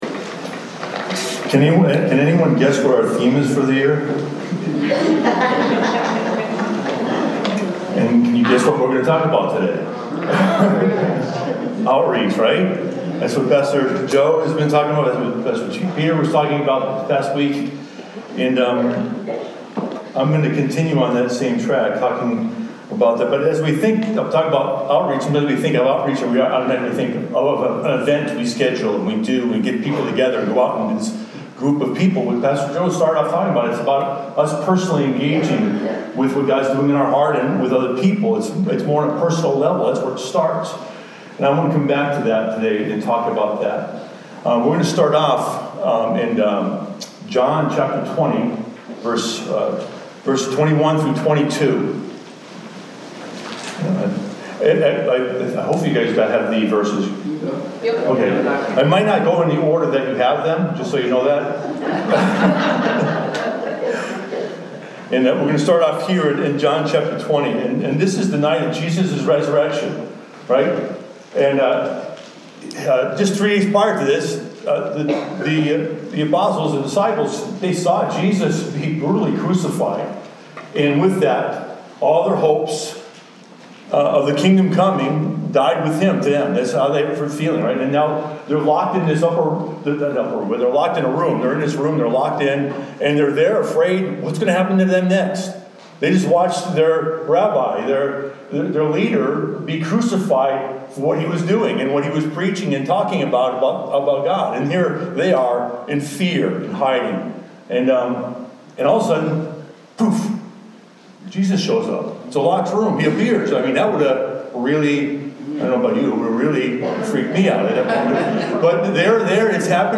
Holy Spirit Discussions